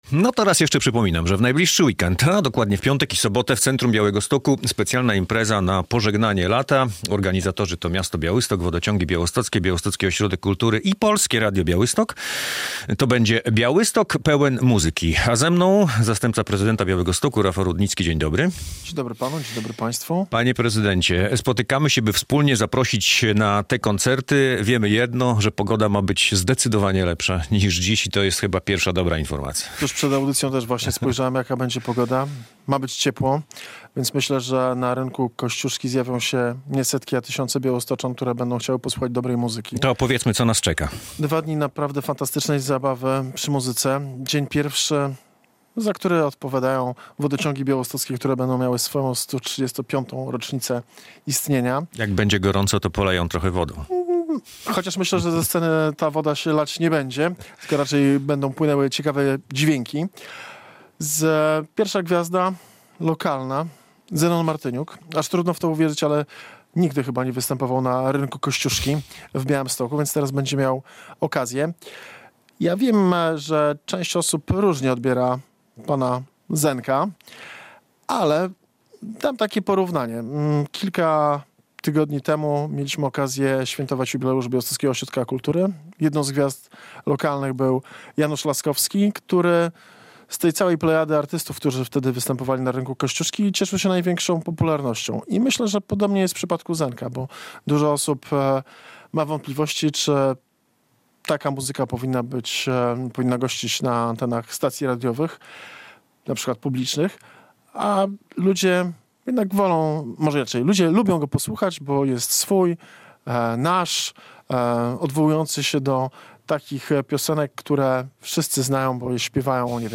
Radio Białystok | Gość | Rafał Rudnicki - zastępca prezydenta Białegostoku
O szczegółach opowiadał zastępca prezydenta Białegostoku - Rafał Rudnicki.